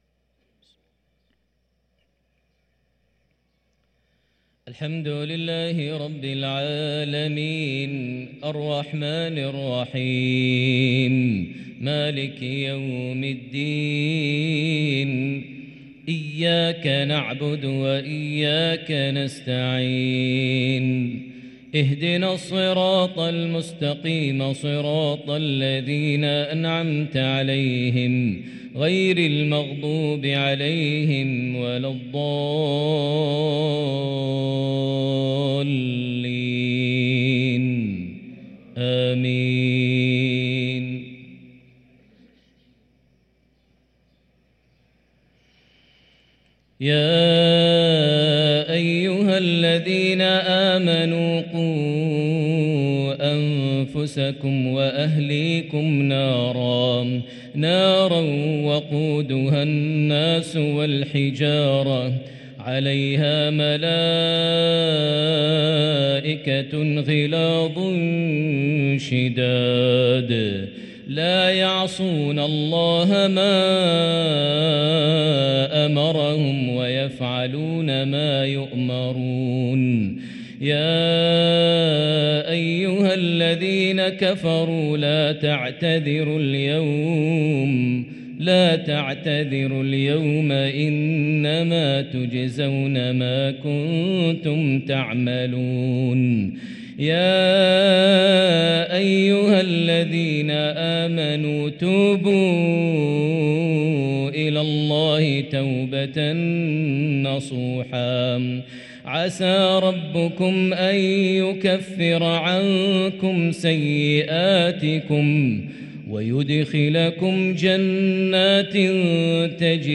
| Maghrib prayer from Surat At-Tahrim 9-3-2023 > 1444 H > Prayers - Maher Almuaiqly Recitations